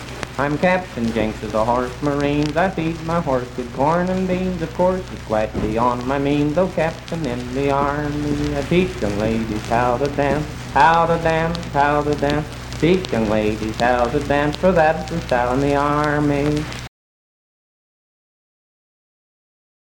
Unaccompanied vocal performance
Verse-refrain 1(8).
Dance, Game, and Party Songs
Voice (sung)